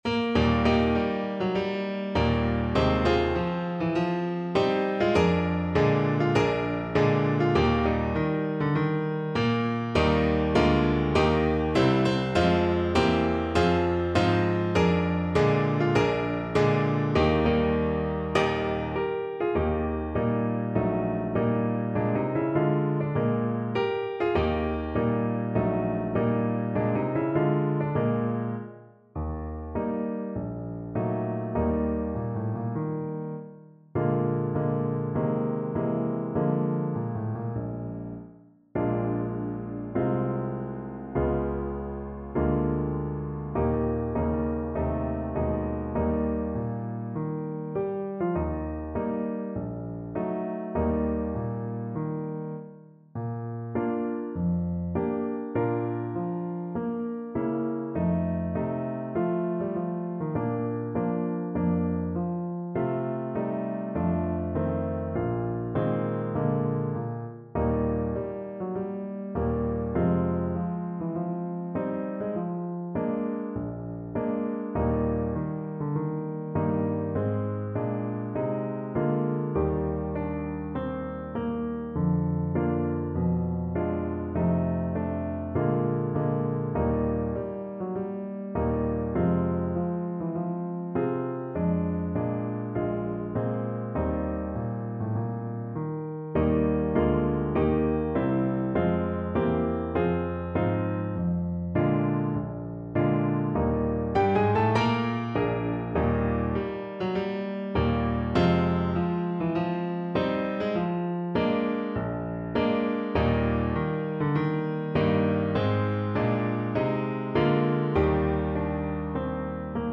Alto Saxophone
Allegro moderato (View more music marked Allegro)
4/4 (View more 4/4 Music)
Classical (View more Classical Saxophone Music)